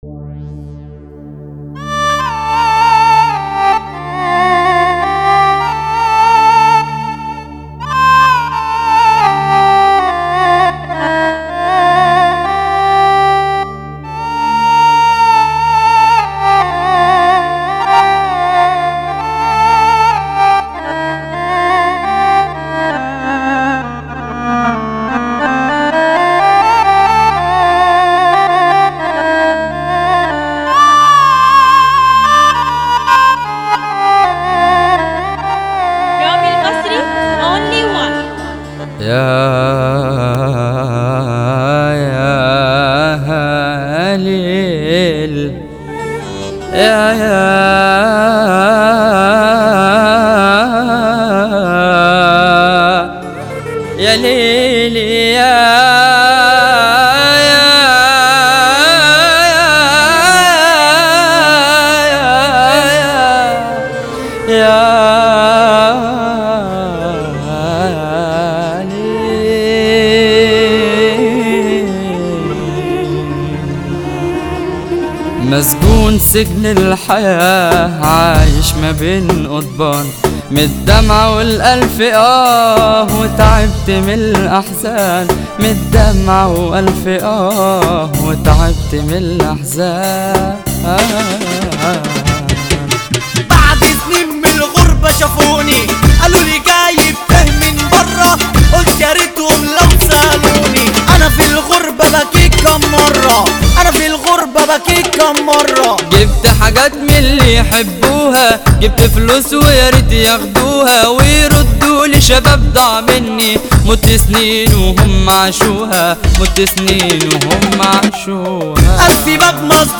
Mahrgan